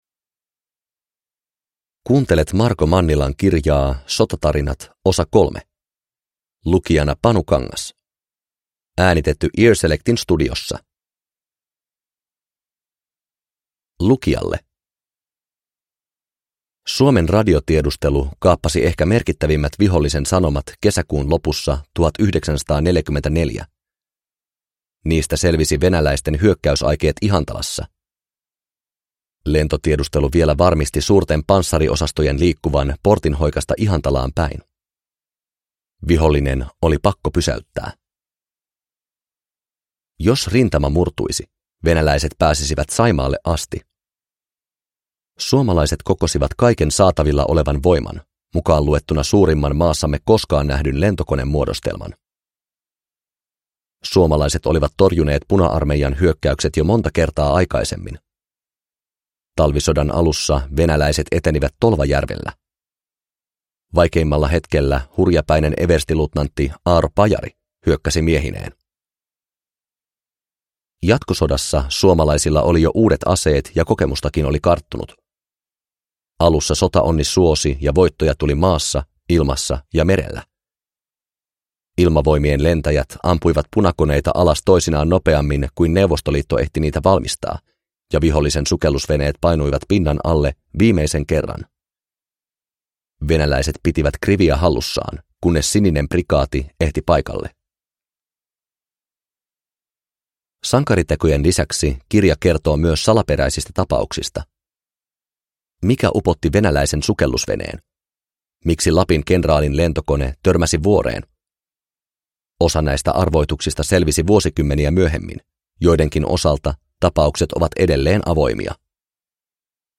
Sotatarinat 3 – Ljudbok – Laddas ner